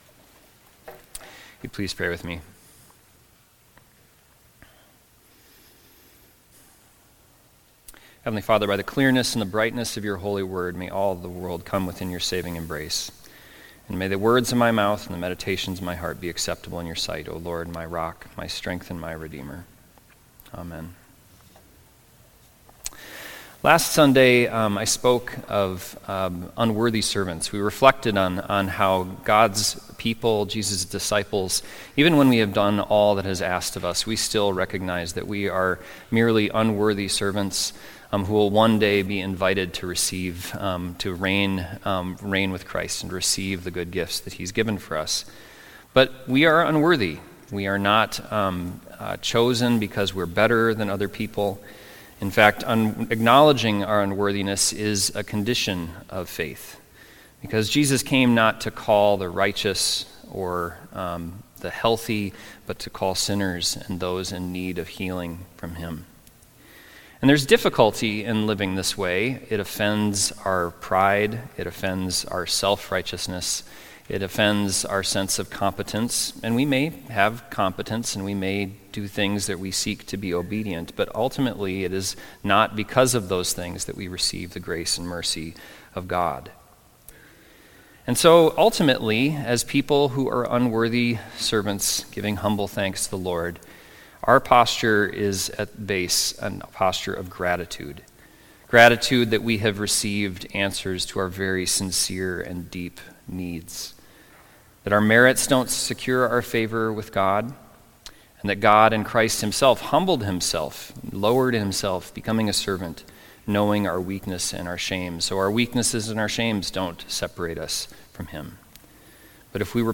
Sunday Worship–October 12, 2025
Sermons